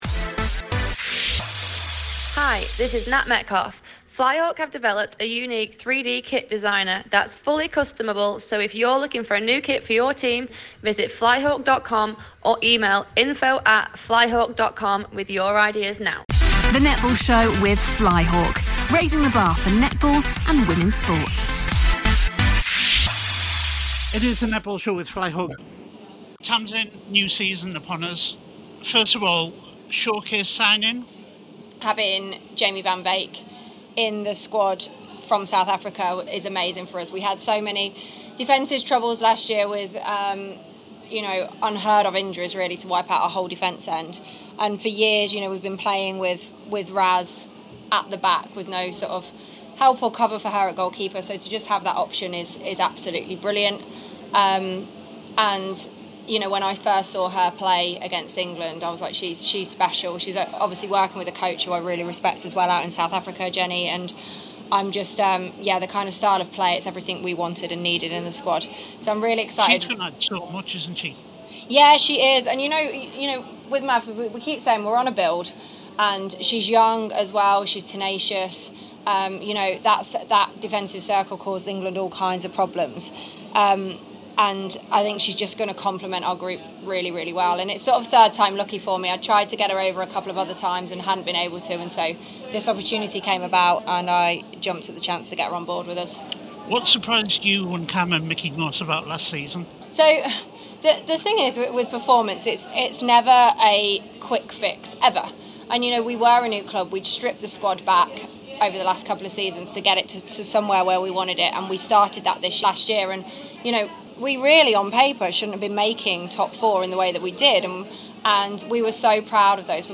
at Mavs recent season launch